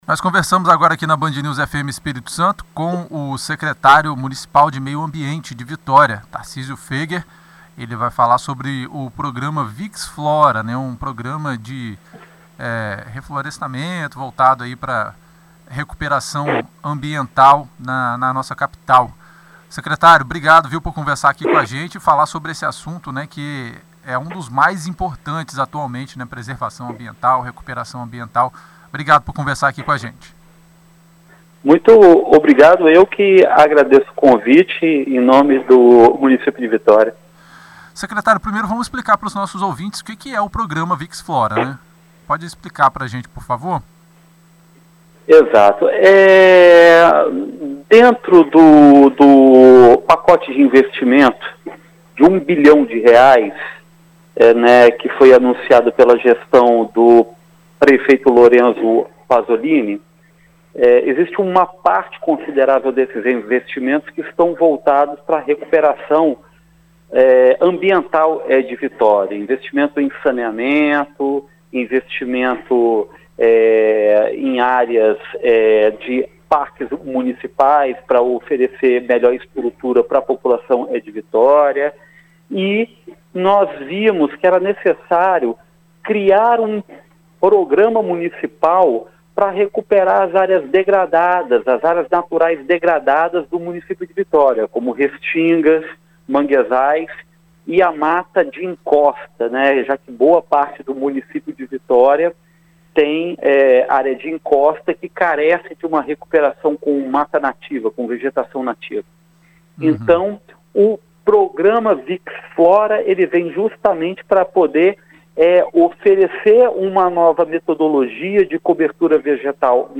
Em entrevista à BandNews FM Espírito Santo, o secretário de Meio Ambiente de Vitória, Tarcísio Föeger, traz os detalhes sobre o Programa Vix Flora.